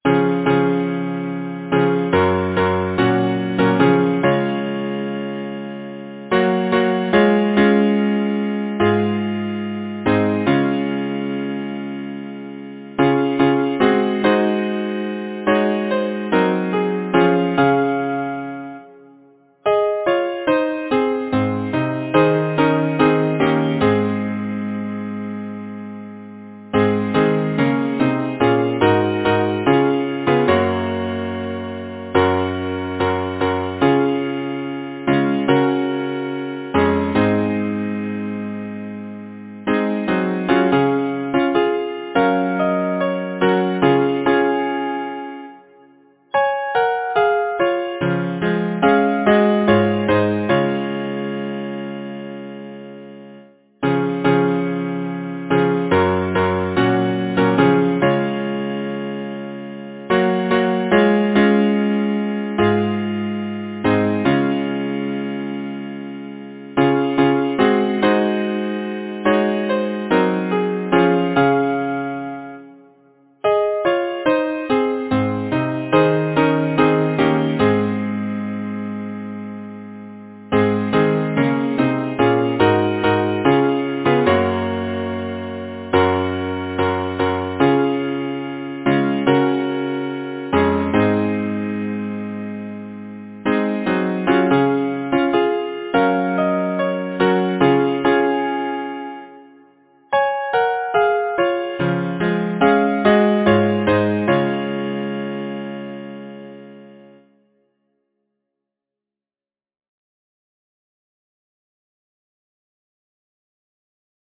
Title: There’s music everywhere Composer: Smith Newell Penfield Lyricist: H. G. Boughancreate page Number of voices: 4vv Voicing: SATB Genre: Secular, Partsong
Language: English Instruments: A cappella